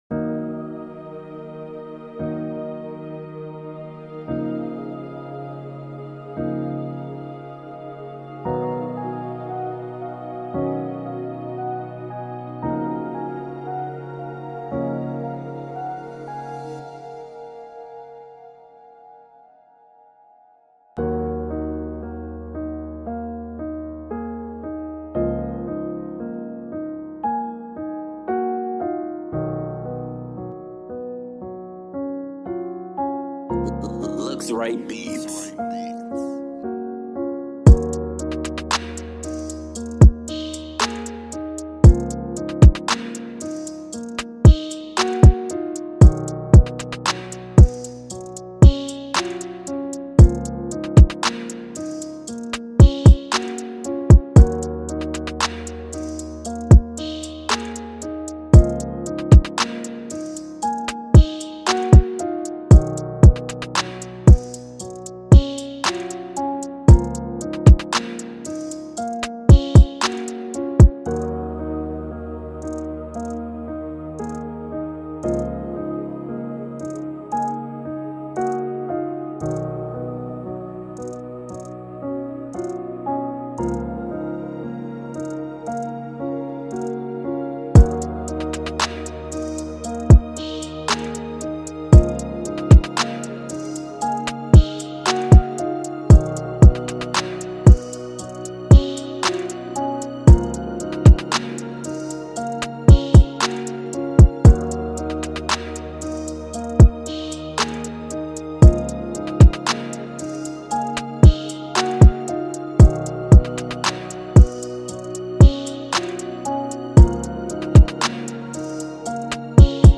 ژانر : ترپ مود : دلنوشت | لاو و دیس لاو تمپو : 115 زمان